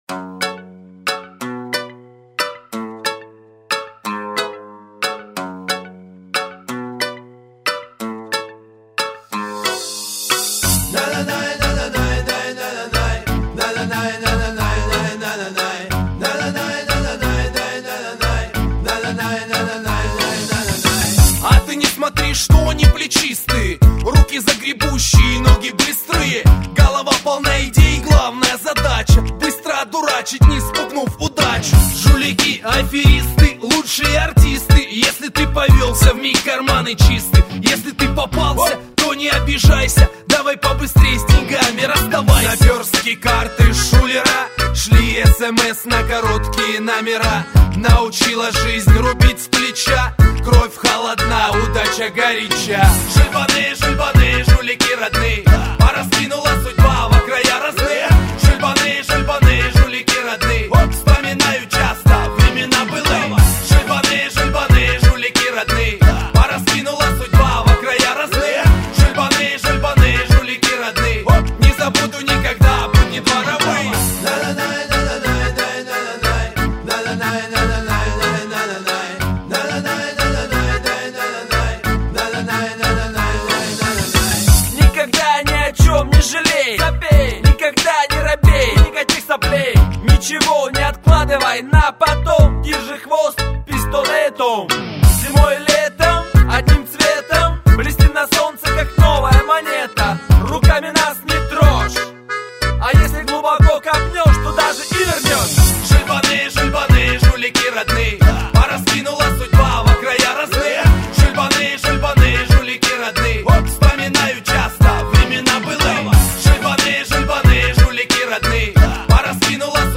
Жанр-русский реп